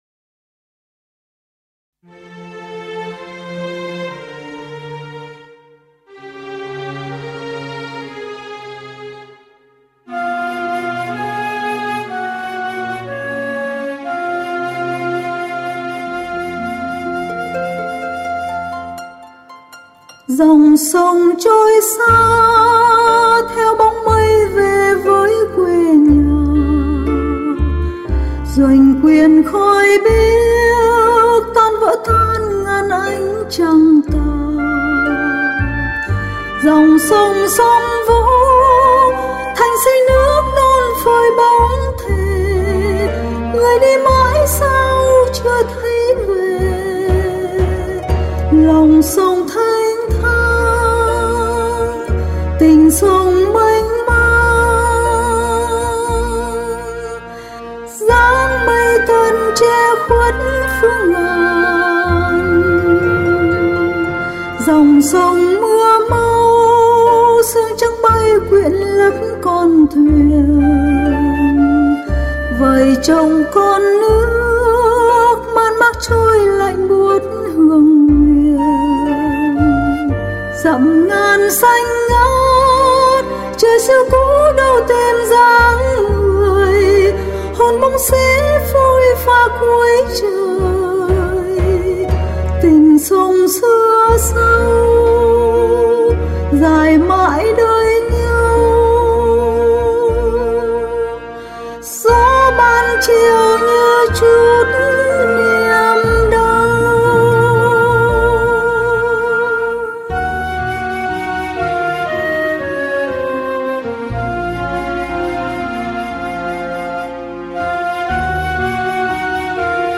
22/02/2021 in Âm Nhạc